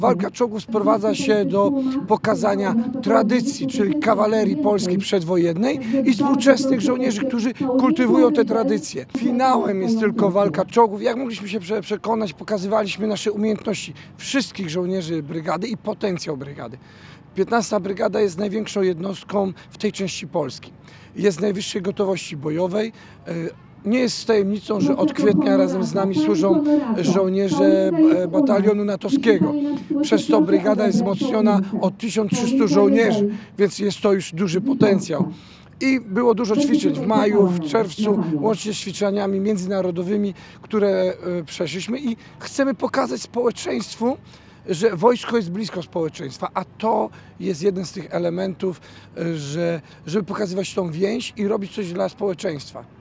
– Wydarzenie to ma na celu pokazanie połączenia tradycji z współczesnością Polskiej Armii – mówi Generał Jarosław Gromadziński, dowódca 15 Giżyckiej Brygady Zmechanizowanej.